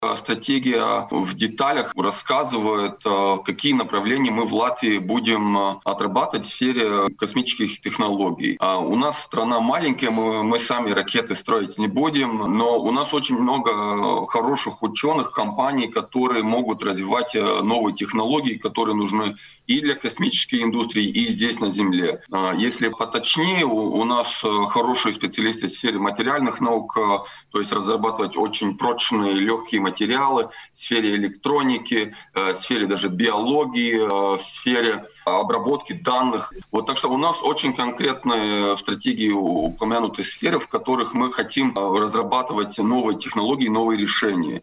А сегодня на радио Baltkom ведущие обсуждали с экспертами целый пакет вопросов - говорили об уборке снега с улиц столицы, об освоении Латвией космоса,  о вторичном рынке автомашин.